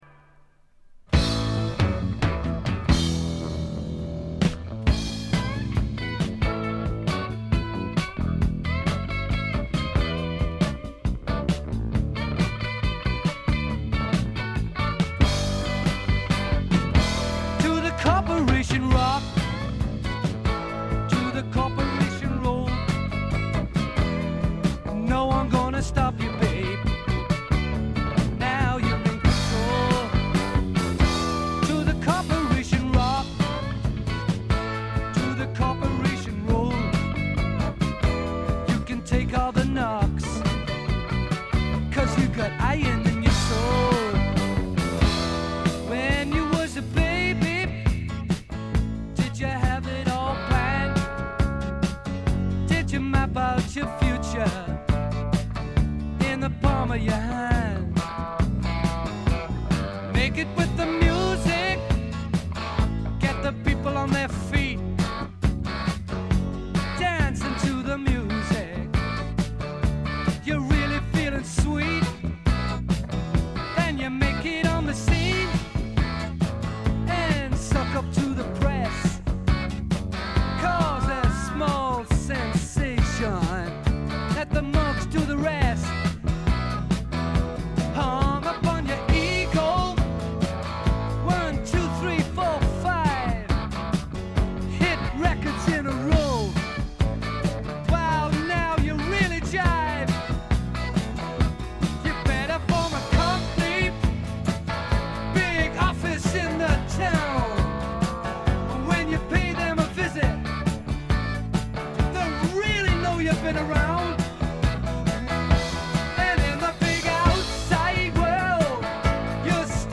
軽いチリプチ少々。
パブロック風味満載、いぶし銀の英国フォークロックです。
試聴曲は現品からの取り込み音源です。